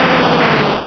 Cri de Kangourex dans Pokémon Rubis et Saphir.